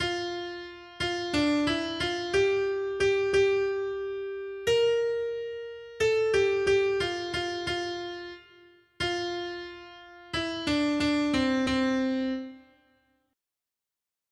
Noty Štítky, zpěvníky ol60.pdf responsoriální žalm Žaltář (Olejník) 60 Ž 2, 1-11 Skrýt akordy R: Dám ti v majetek národy. 1.